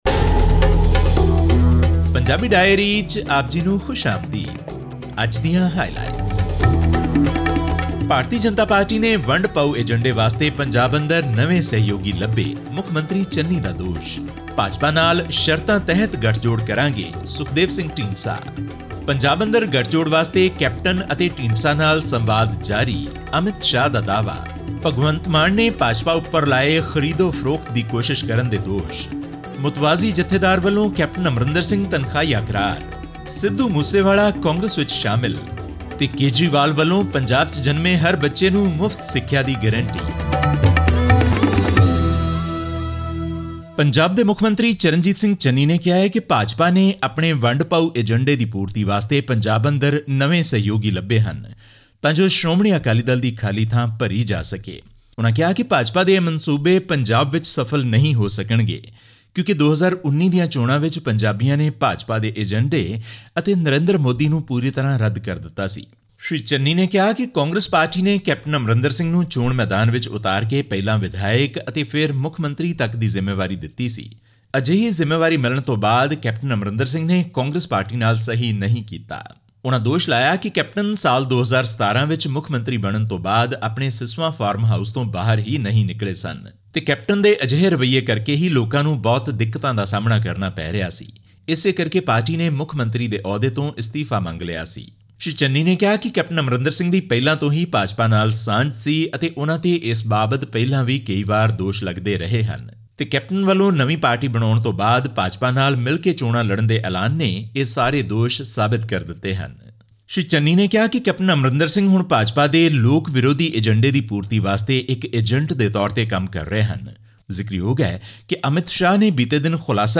Famous Punjabi singer and rapper Sidhu Moosewala, who was booked last year for allegedly promoting gun culture and violence, has joined Congress ahead of the state assembly polls. This and more in our weekly news update from India's northern state.